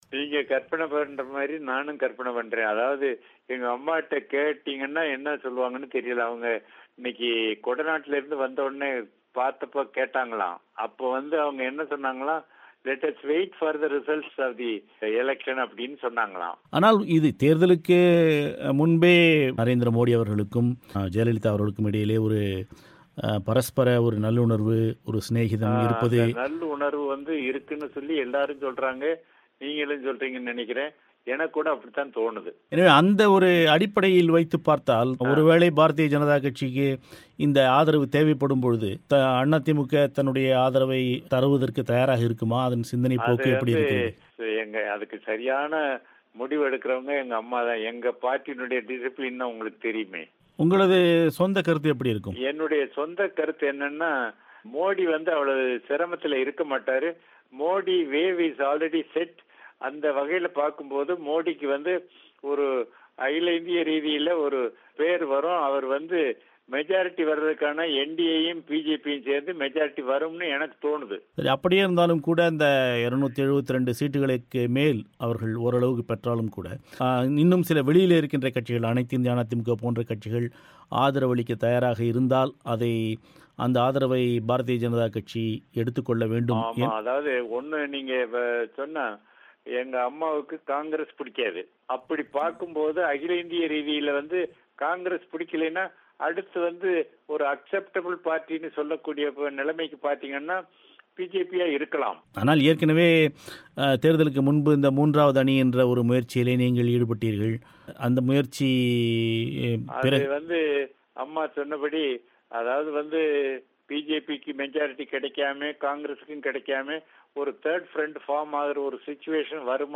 பாஜகவுக்கு அதிமுக ஆதரவு தருமா ? - மலைச்சாமி செவ்வி